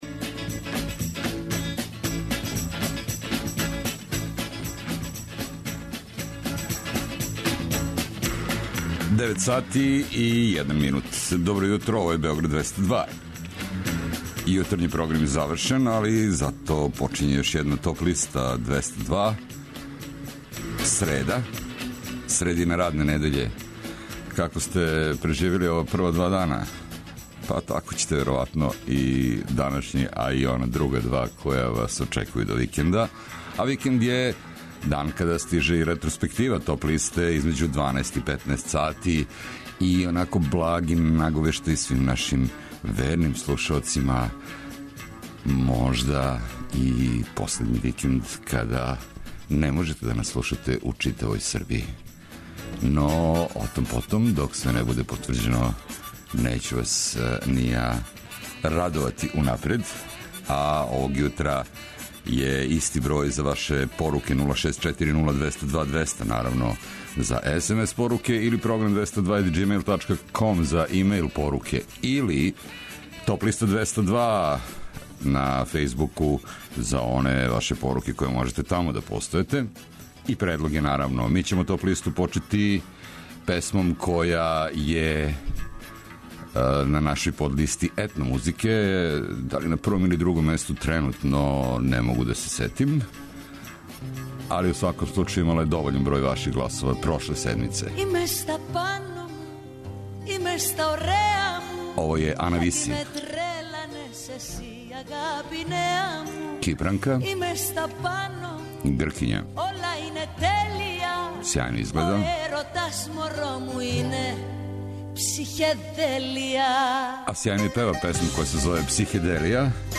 Знате ли који музичаре славе рођендане почетком новембра? Чије ћемо концерте моћи да гледамо (и слушамо) до краја године? Све то и још много добре музике моћи ћете да чујете у новој Топ листи 202, у првој седмици новембра, сваког радног дана између 9 и 10 сати.